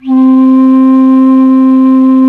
Index of /90_sSampleCDs/Roland LCDP04 Orchestral Winds/FLT_Alto Flute/FLT_A.Flt nv 3
FLT ALTO F03.wav